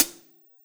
Cymbol Shard 09.wav